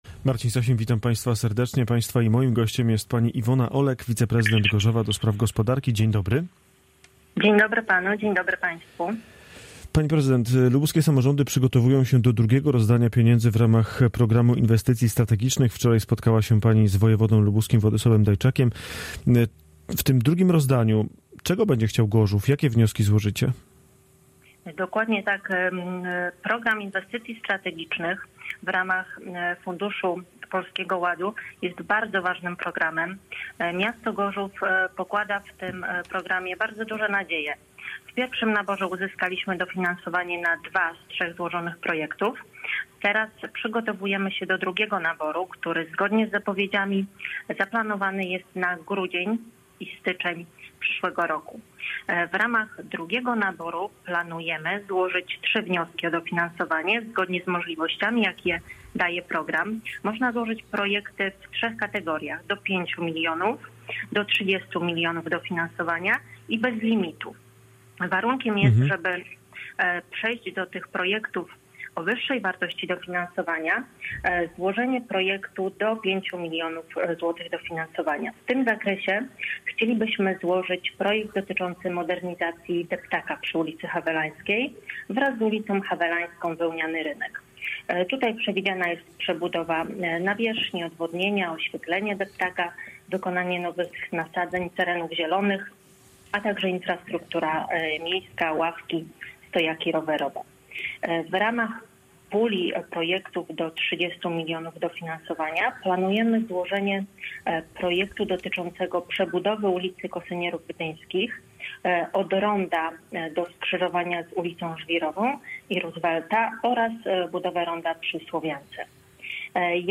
Iwona Olek, wiceprezydent Gorzowa ds. gospodarki
Z wiceprezydentem Gorzowa rozmawia